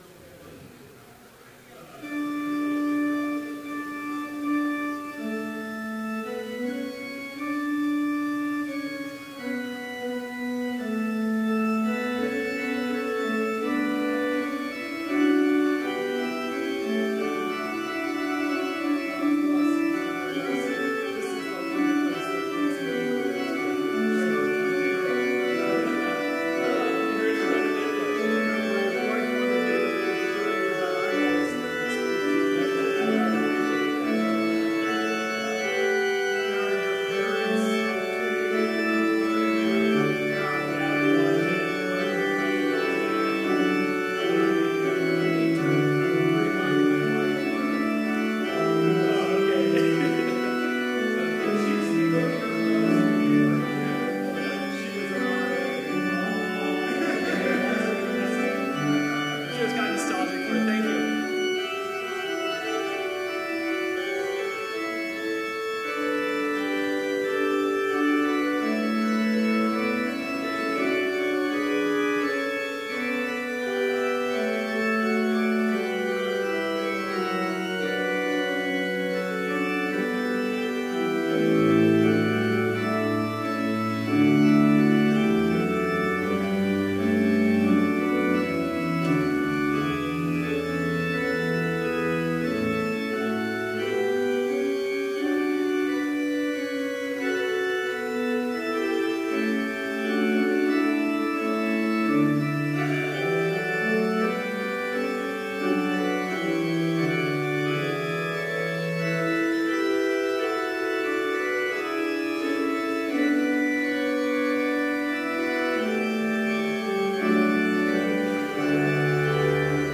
Complete service audio for Chapel - March 23, 2017